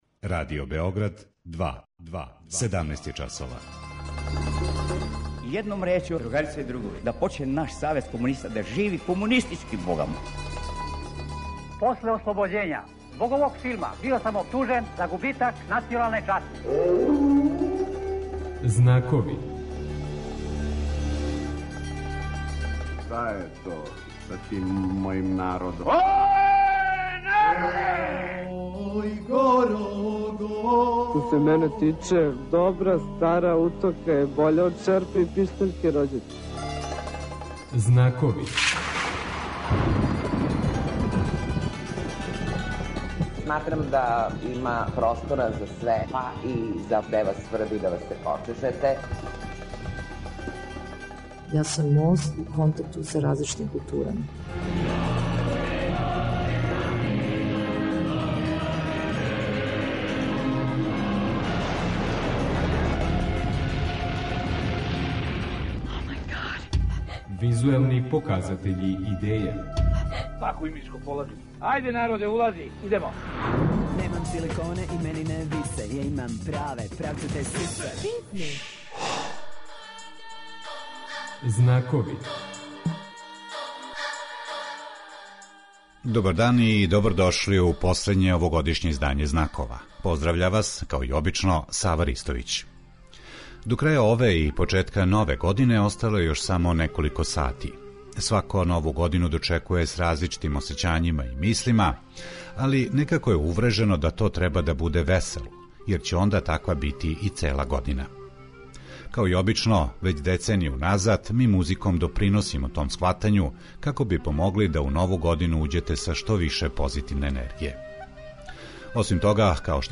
Специјално, музичко издање емисије